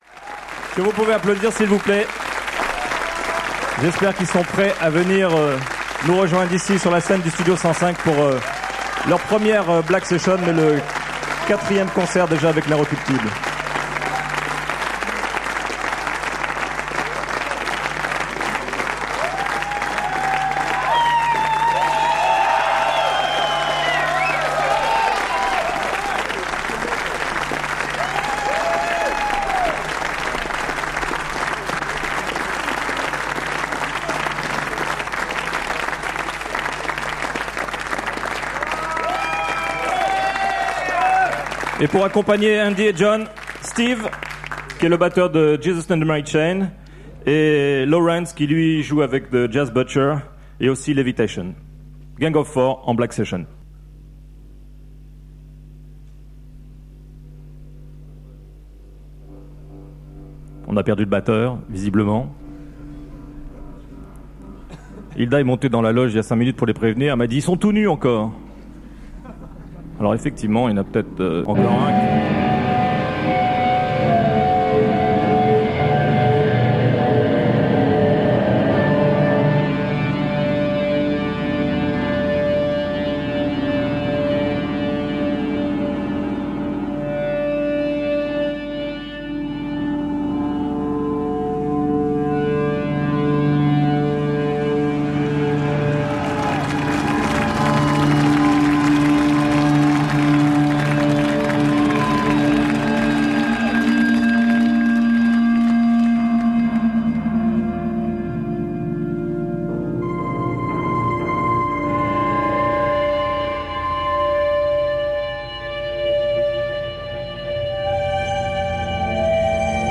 Live at Studio 105, Maison de la Radio
in concert